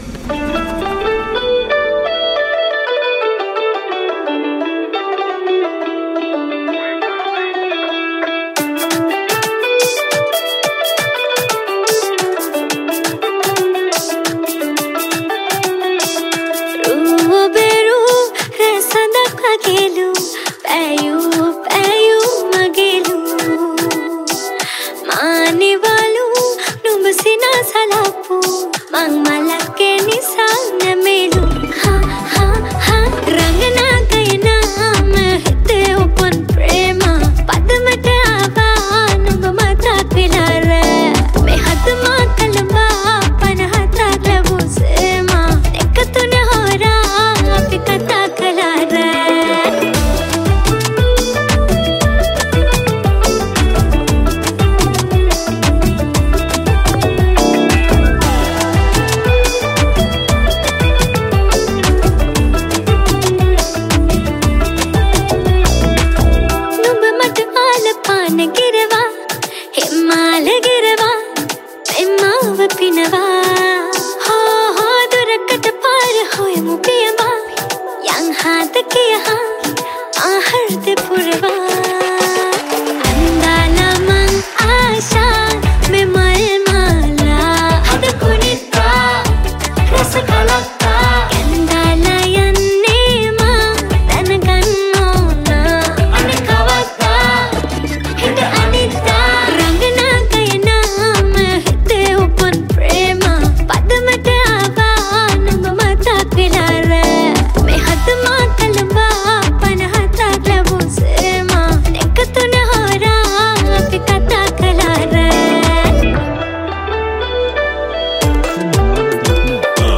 Guitar
Backing Vocals